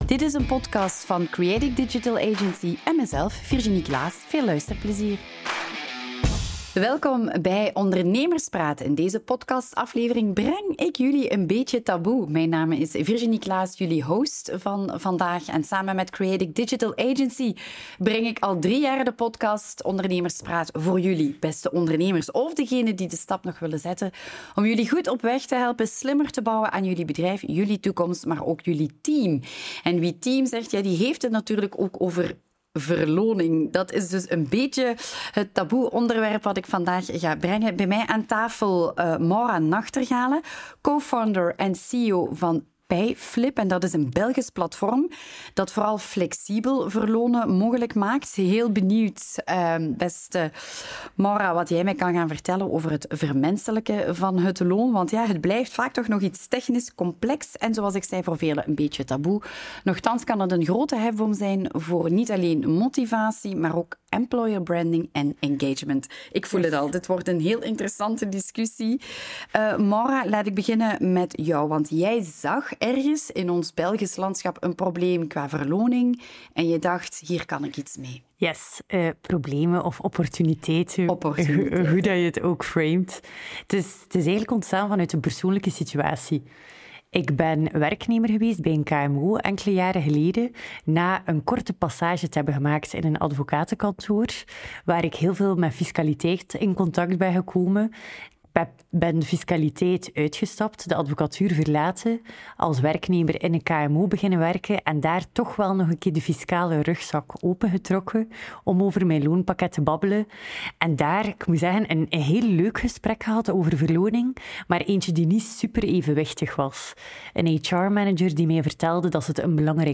Audio narration of: What If Employees Could Choose Their Own Salary?